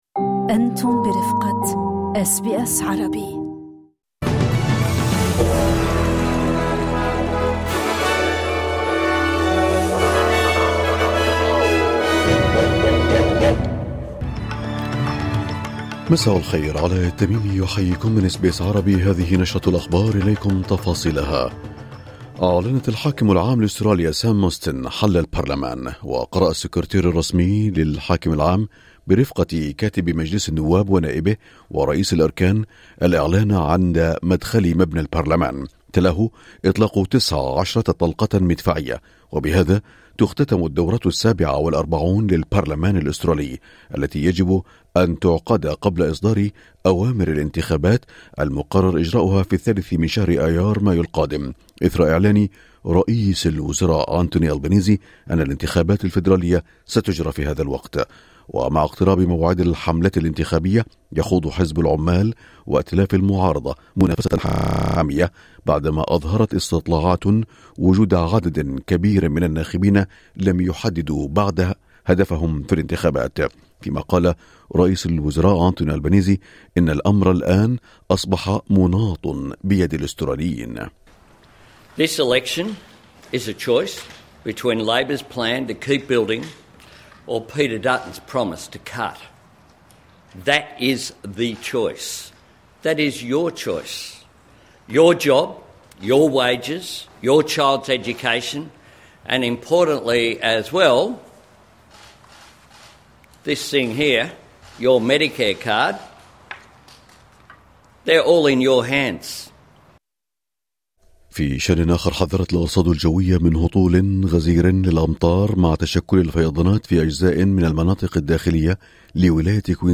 نشرة الظهيرة 28/3/2025